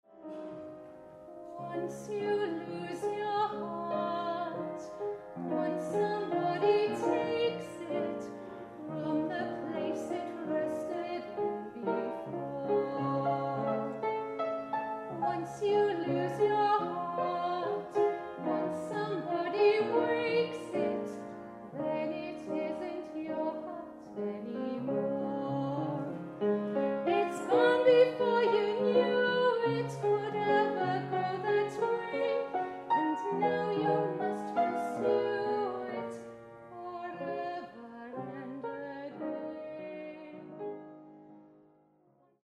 Wedding singer, wedding soloist
These songs were recorded at concerts for Chimes Musical Theatre in various Church halls around London and the South East.